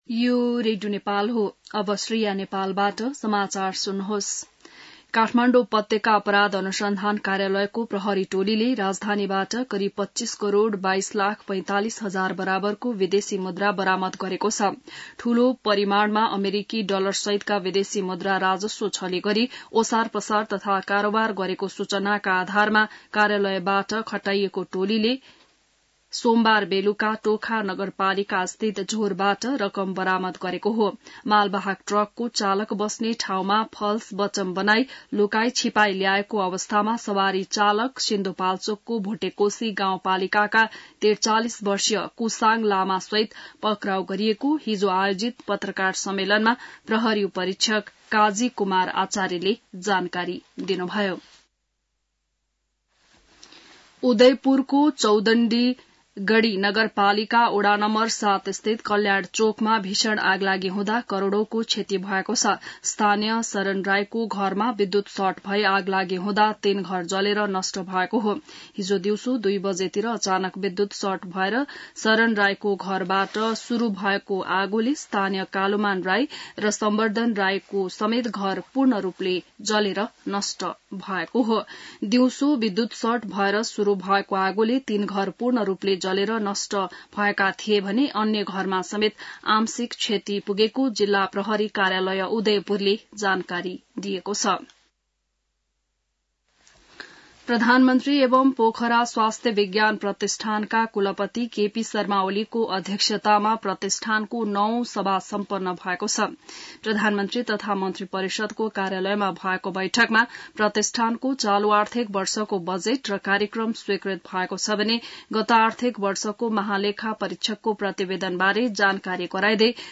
बिहान ६ बजेको नेपाली समाचार : ६ चैत , २०८१